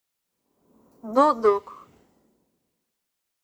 Audio file of the word "Duduk"
Audio-word-duduk.mp3